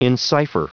Prononciation du mot encipher en anglais (fichier audio)
Prononciation du mot : encipher